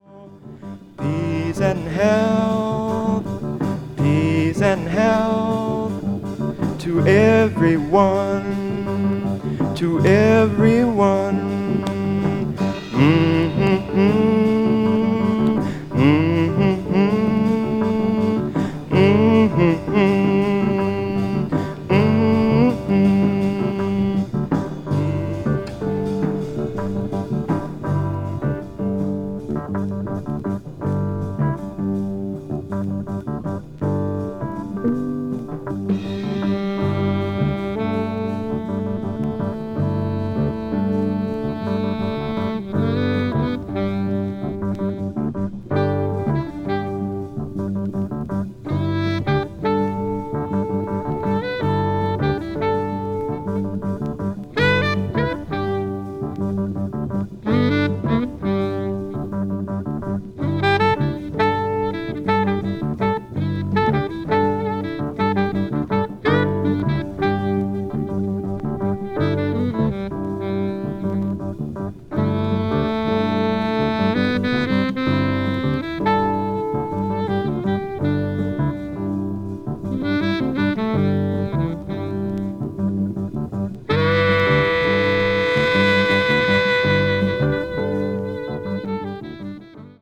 saxophonist
recorded live at the Montreux Jazz Festival in Switzerland